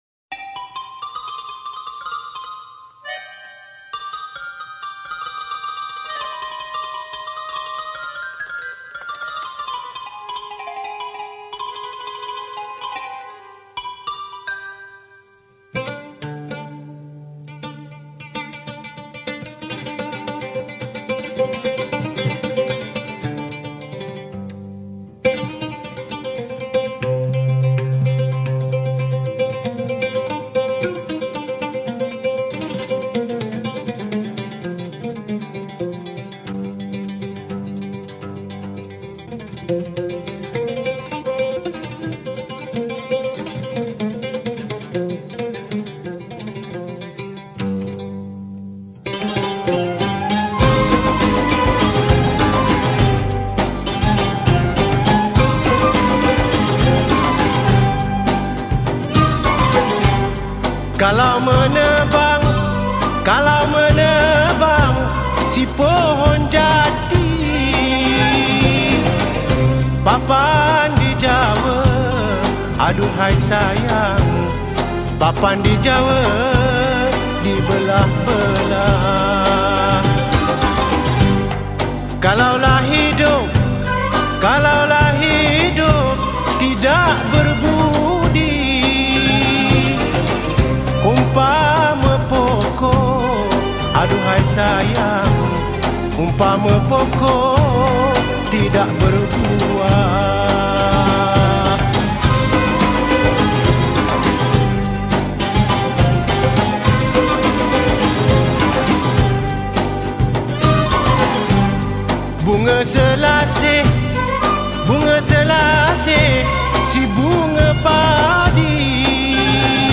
Malay Songs
Skor Angklung